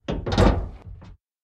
ambienturban_7.ogg